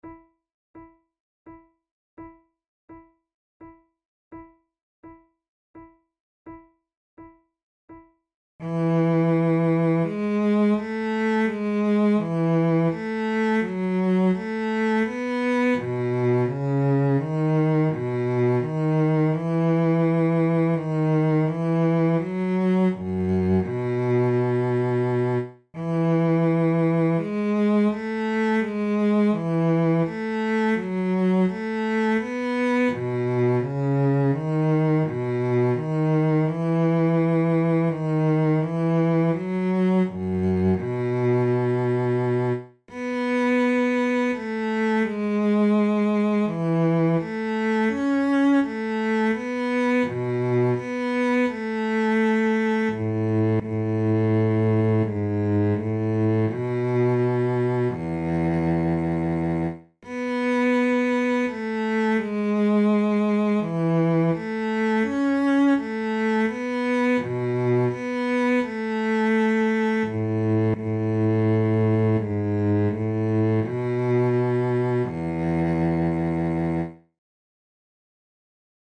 KeyE major
Tempo84 BPM
Baroque, Sonatas, Written for Flute